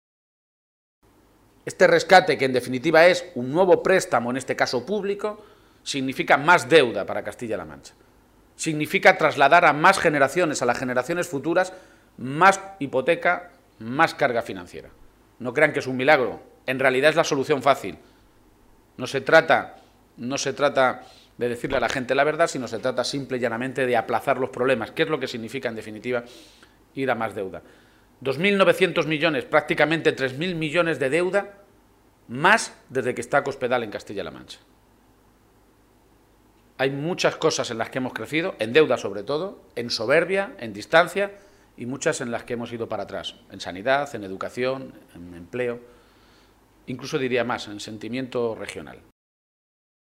El secretario general del PSOE de Castilla-La Mancha, Emiliano García-Page, ha comparecido hoy ante los medios de comunicación minutos antes de la celebración del primer Comité Regional después de Décimo Congreso que le eligió con un 95 por ciento de los votos nuevo máximo dirigente de los socialistas de Castilla-La Mancha.